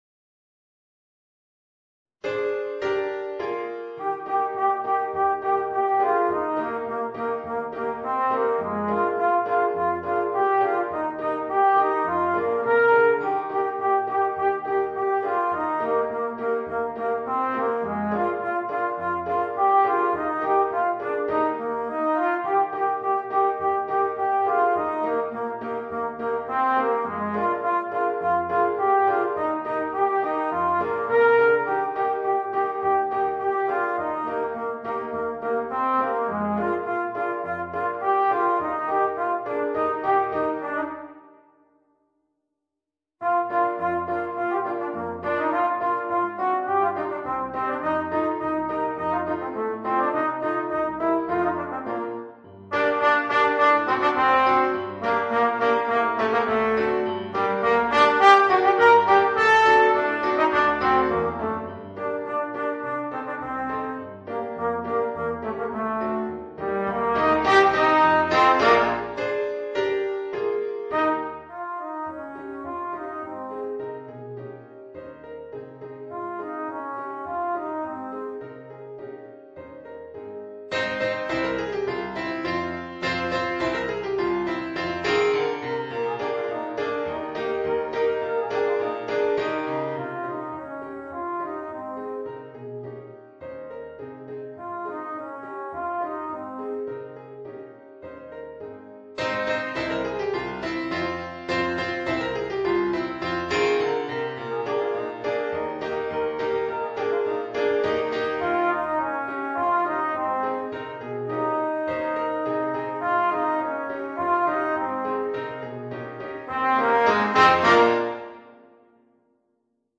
Trombone & Piano (Drums & Percussions optional)